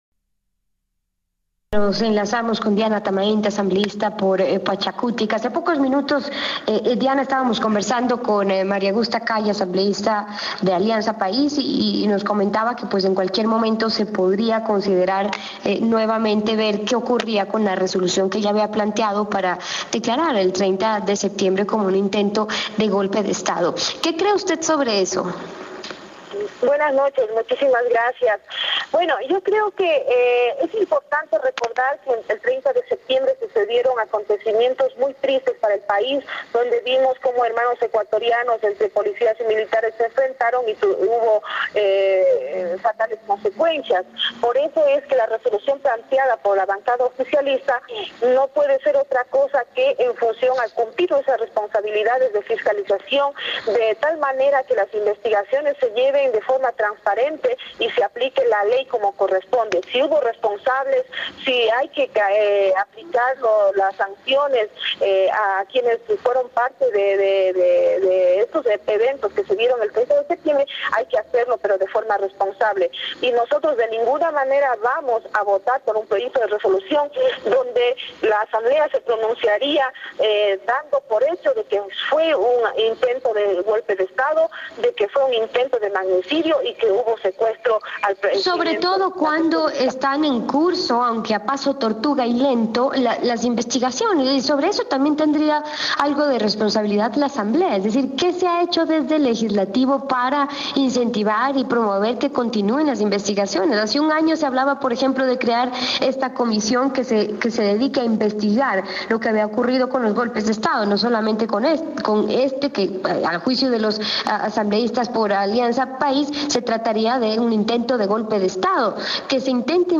diana-atamaint-entrevista-radio-mundo.wma